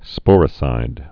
(spôrĭ-sīd)